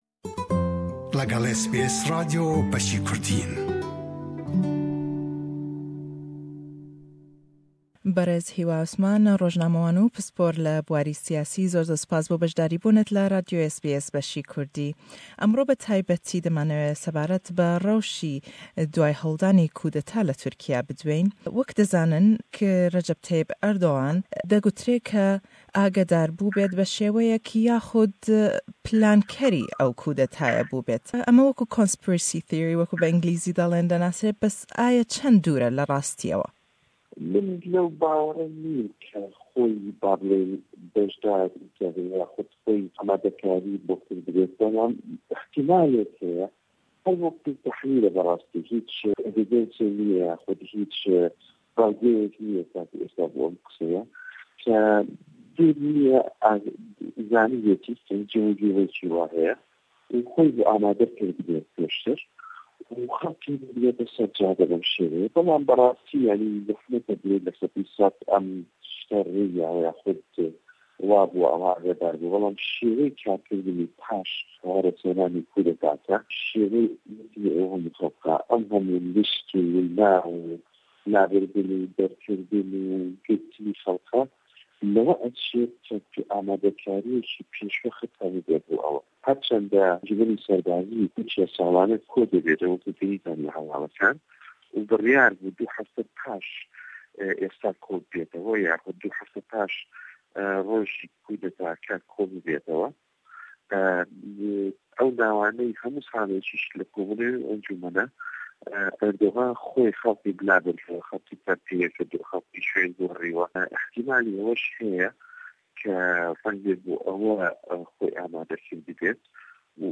Ashkiraye ke Turkiya cêyekî stratîjî heye le ser astî nêwdewlletî û nawçeyî, bellam kardanewey hewlldanî kûdeta le willate, çende? Le em hevpeyvîne da le gell rojnamevan û pisporî siyasî